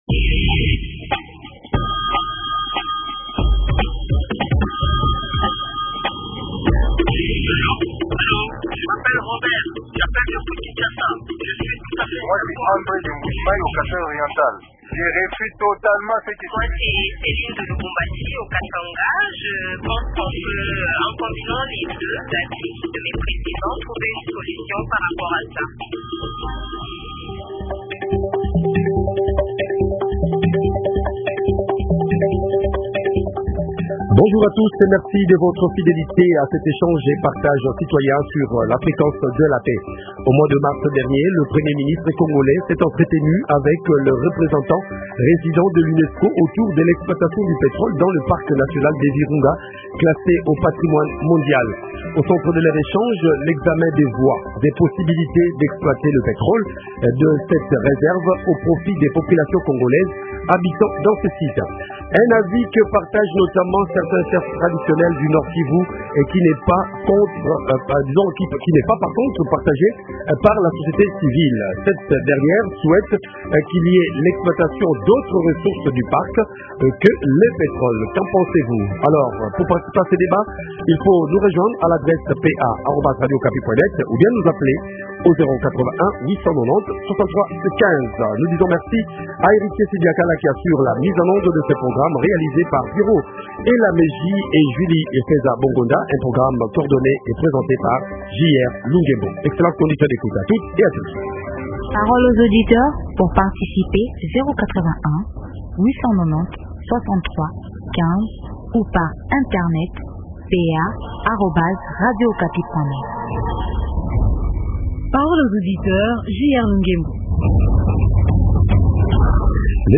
Invité: Jean Uyange, Ministre provincial des hydrocarbures, Ressources hydrauliques et Mines au Nord-Kivu.